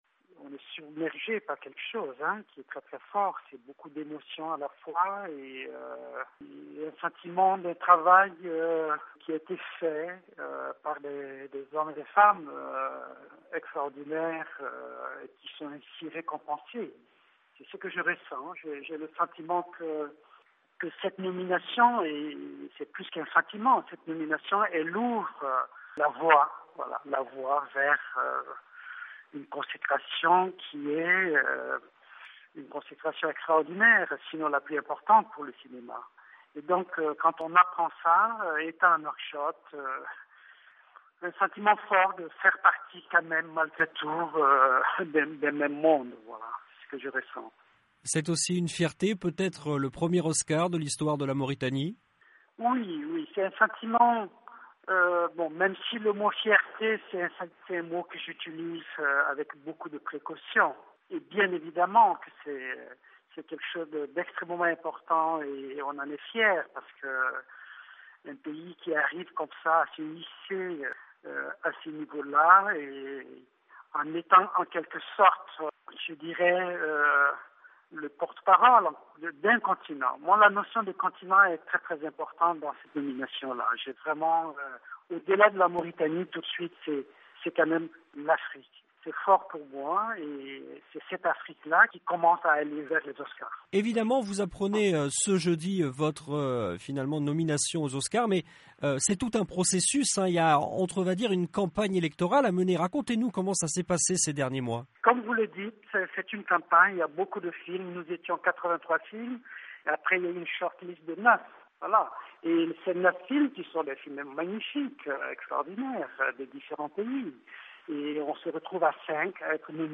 Joint par VOA Afrique à Nouakchott, le réalisateur se dit "submergé par beaucoup d’émotions. J’ai le sentiment d’un travail fait des par des gens extraordinaires qui sont ainsi récompensés. C’est nomination ouvre la voie vers une consécration la plus importante pour le cinéma. Apprenant la nouvelle, en étant à Nouakchott, j’ai le sentiment que nous faisons partie, malgré tout, d’un même monde."